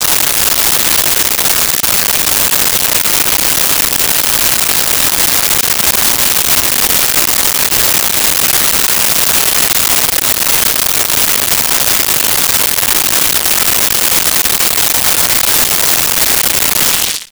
Rain
Rain.wav